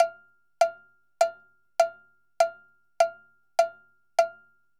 Timbaleta_Salsa 100_1.wav